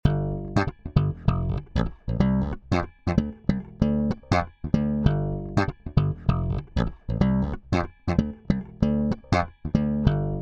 Zu meiner Überraschung war das Geräusch immernoch da, nachdem ich die Saiten oben abgedämmt hatte. Hat jemand eine Ahnung wie das Geräusch entsteht und vor allem wie man es abstellen kann?